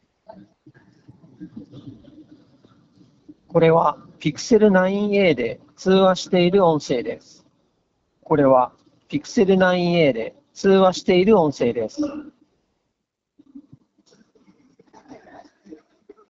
実際にバックグラウンドで喧騒音を大きめに流しながら通話音声を録音したのでチェックしてください。
そしてこちらがGoogle Pixel 9aで通話している音声です。
音声はどちらもクリアで聞き取りやすいですが、周囲の雑音の聞こえ方が全く違いますよね。
pixel-aquos-sense10-voice.m4a